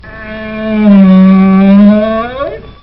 دانلود آهنگ شتر 2 از افکت صوتی انسان و موجودات زنده
دانلود صدای شتر 2 از ساعد نیوز با لینک مستقیم و کیفیت بالا
جلوه های صوتی